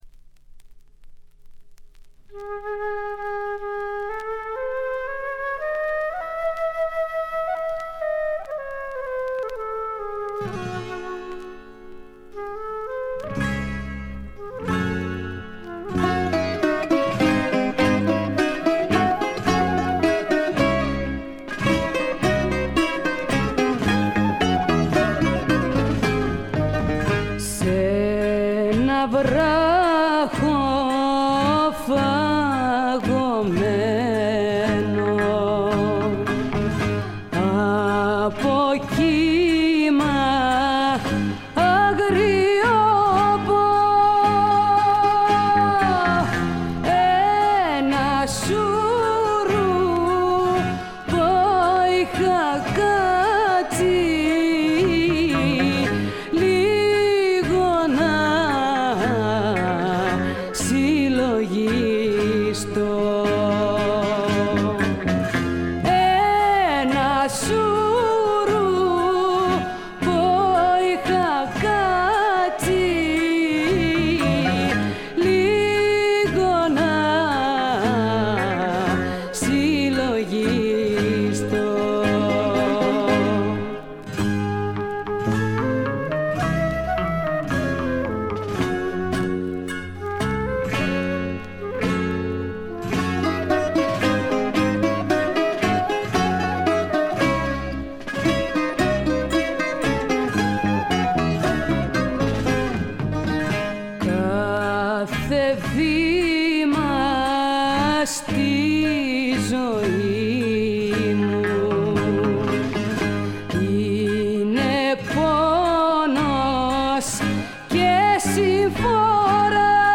わずかなノイズ感のみ。
しかしデビュー時にしてすでに堂々たる歌唱を聴かせてくれます。
試聴曲は現品からの取り込み音源です。